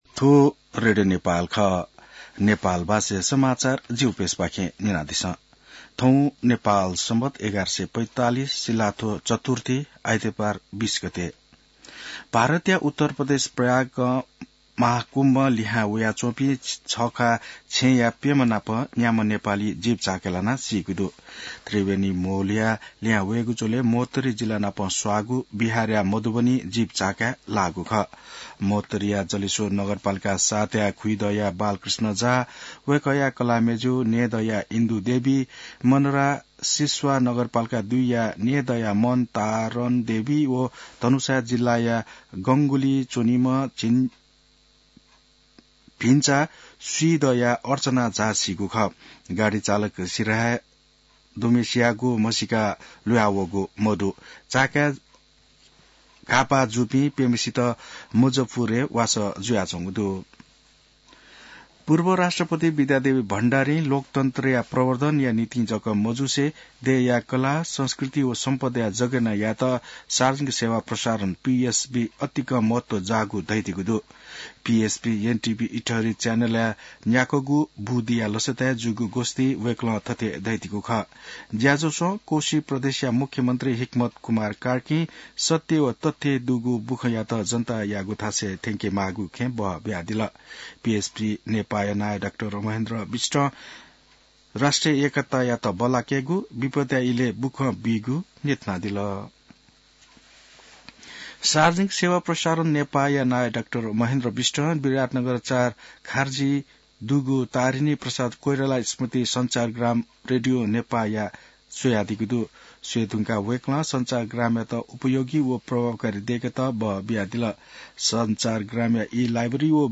नेपाल भाषामा समाचार : २१ माघ , २०८१